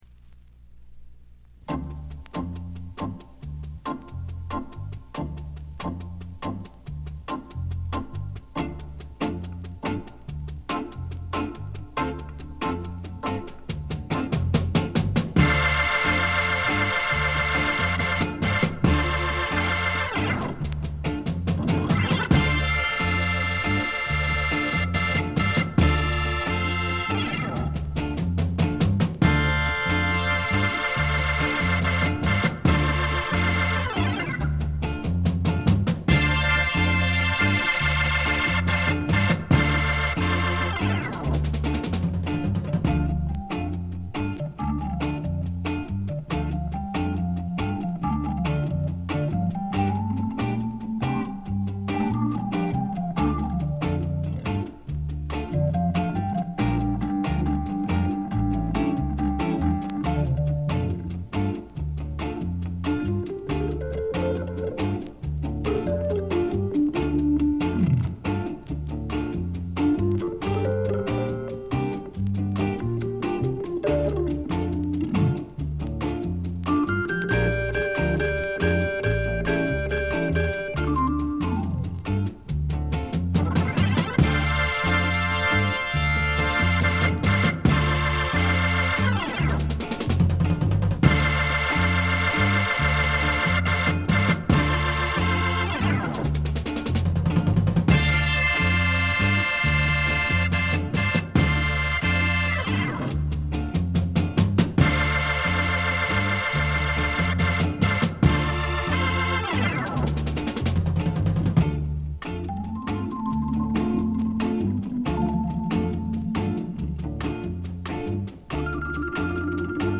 全体にチリプチ、プツ音多め大きめ。A4後半の周回ノイズ、B2フェードアウト部分あたりが目立つノイズです。
試聴曲は現品からの取り込み音源です。